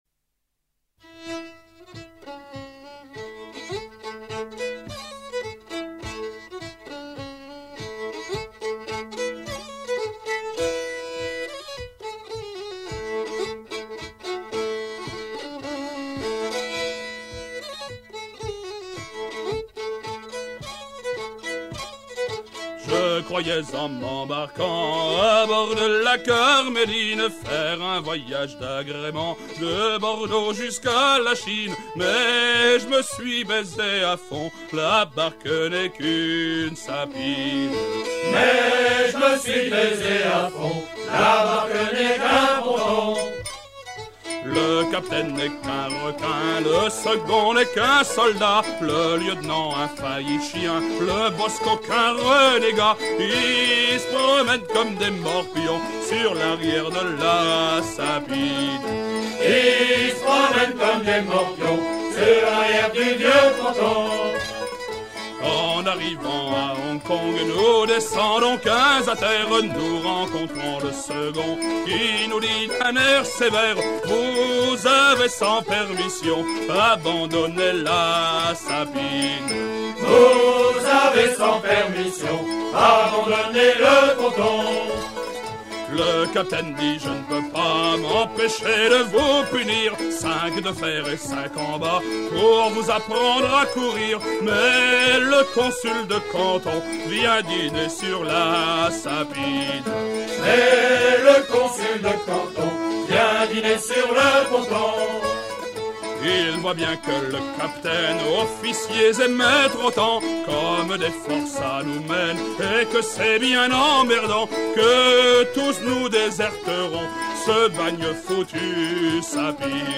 vrais chants de marins
Pièce musicale éditée